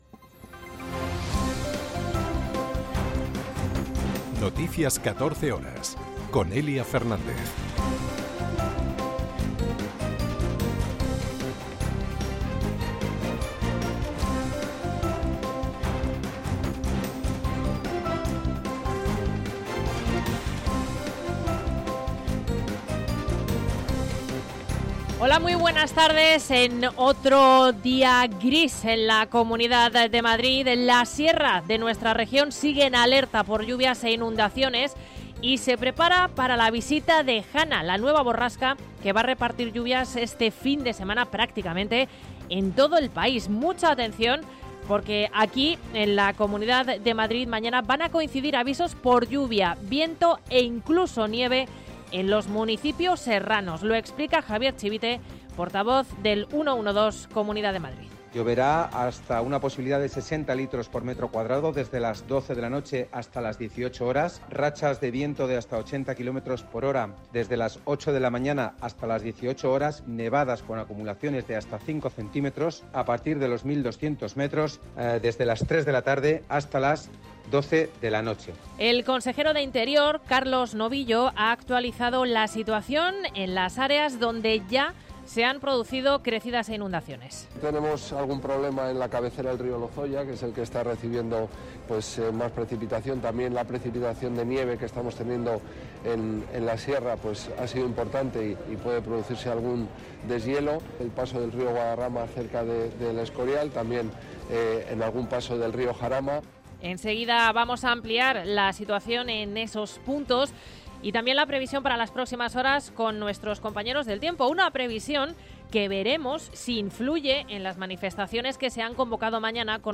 en España y en el mundo. 60 minutos de información diaria con los protagonistas del día y conexiones en directo en los puntos que a esa hora son noticia.